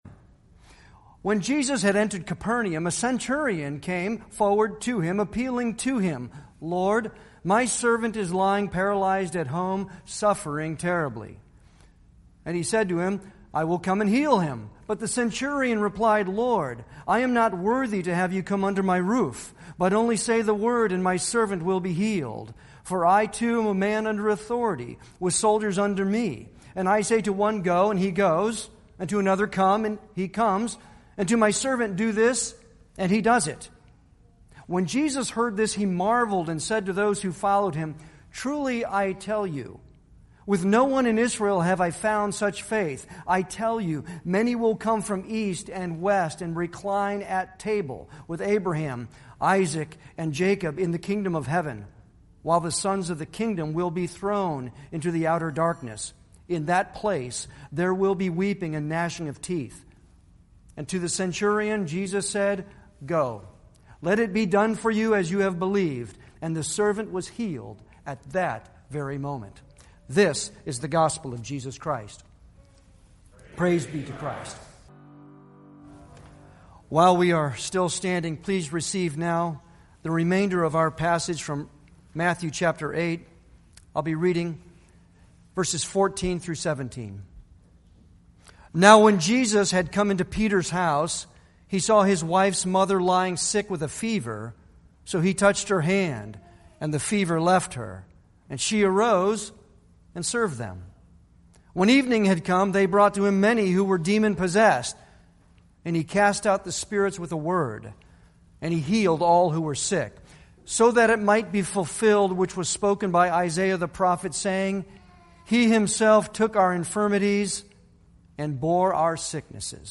Series: Sermons on the Gospel of Matthew
Service Type: Sunday worship